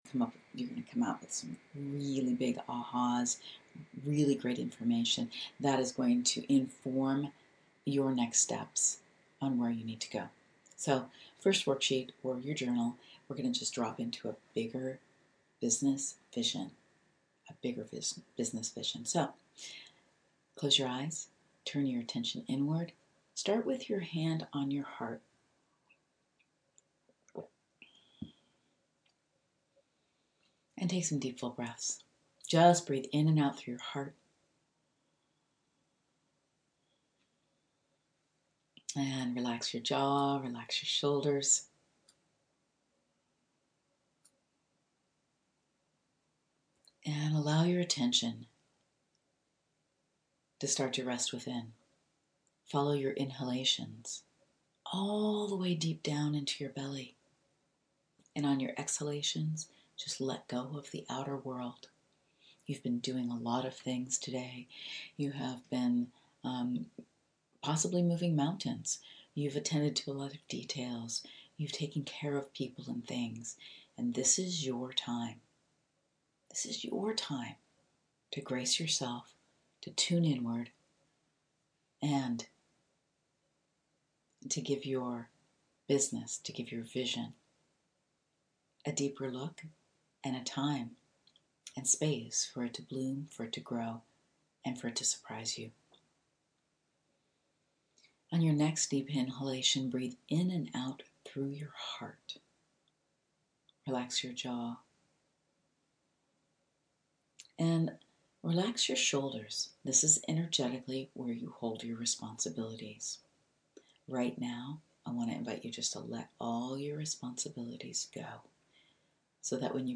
Big-Business-Vision-Meditation.mp3